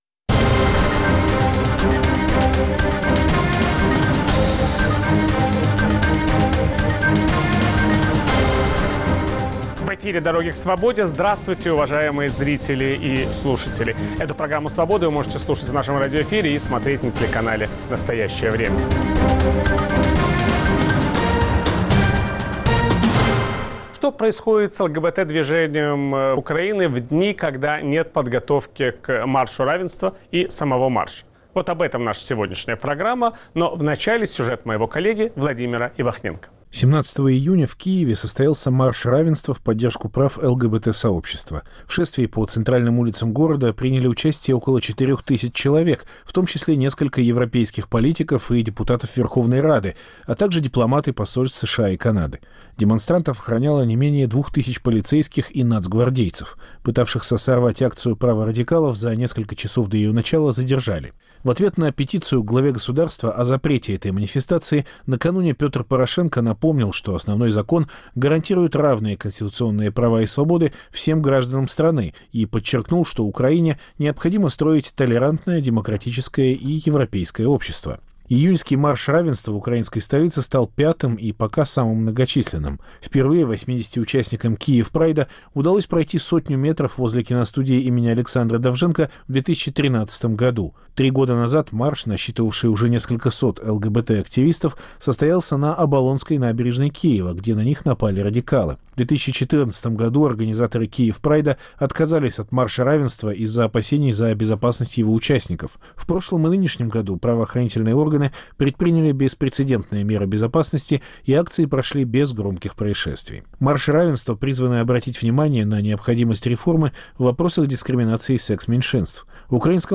В киевской студии Радио Свобода обсуждаем Украину после Майдана. Удастся ли украинцам построить демократическое европейское государство? Как складываются отношения Украины и России?
Все эти и многие другие вопросы обсуждаем с политиками, журналистами и экспертами.